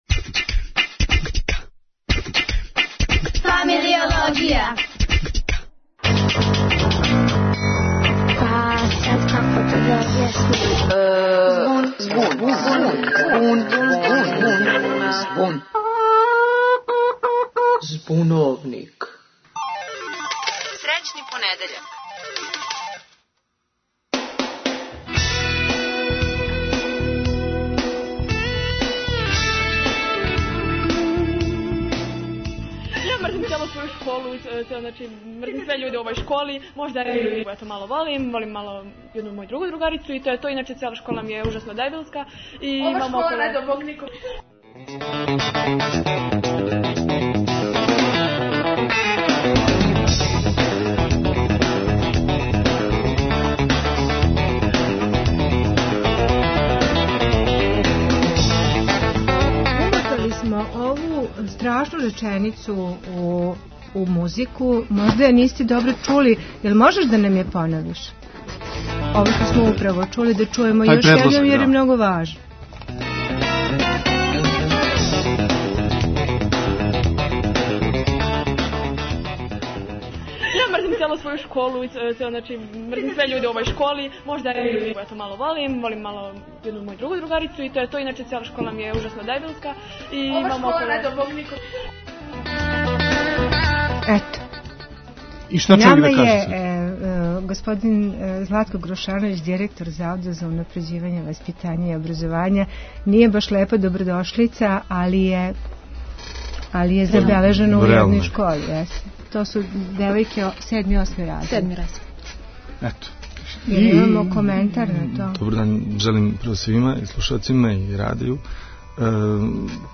Гост у студију је Златко Грушановић, директор Завода за унапређење образовања и васпитања.